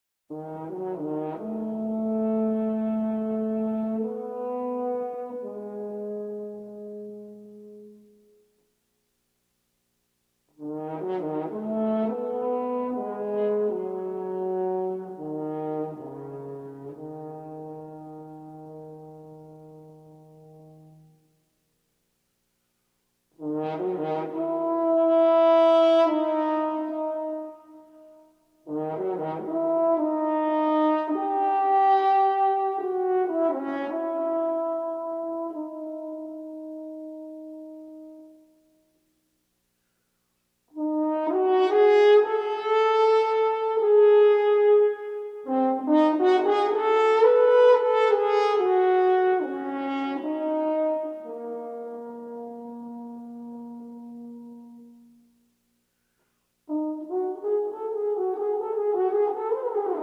horn